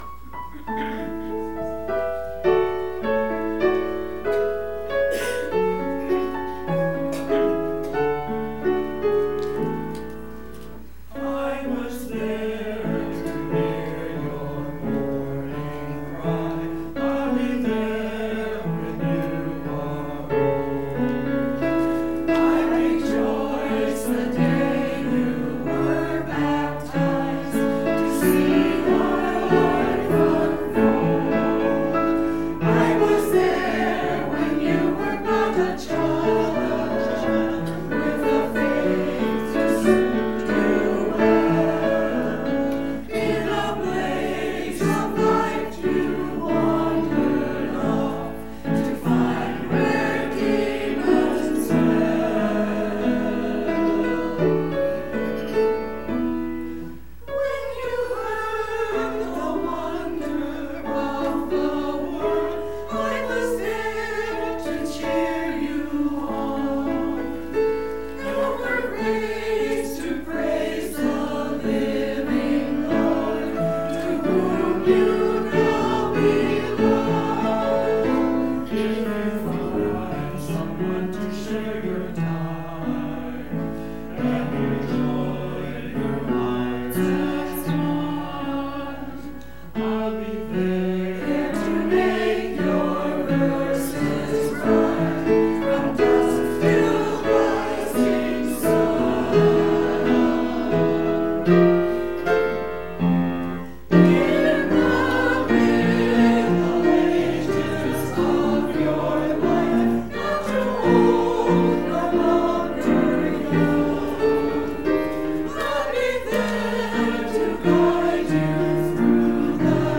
Church Choir – Borning Cry 1.13.19
To hear the church choir praise God please click play below.